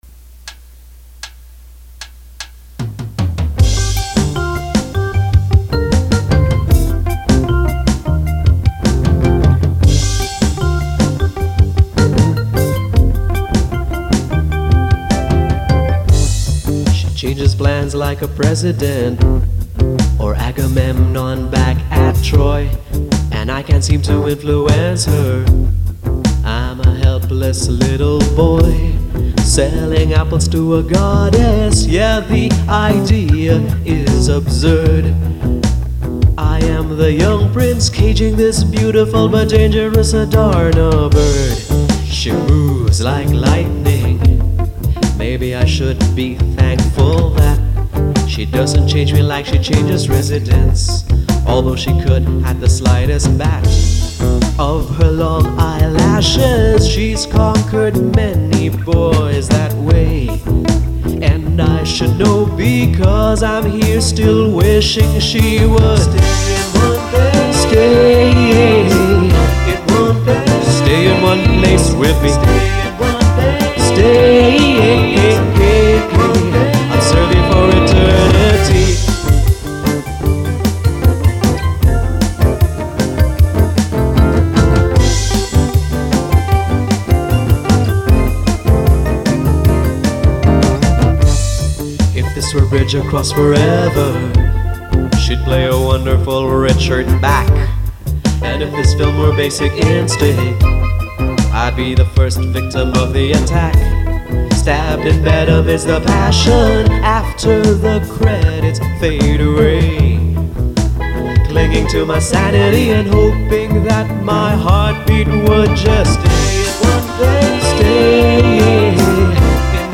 The only other recording I have of this song was a crappy-sounding demo done in 1999 which we included in our wedding souvenir giveaway.
Apparently when I transferred it from my 4-track cassette recorder to hard drive, it was sped up for some reason. So I sound like a throaty chipmunk. Also, it looks like I took out the wedding theme interlude before the final chorus. And you HAVE to love that chaotic rock-n-roll ending.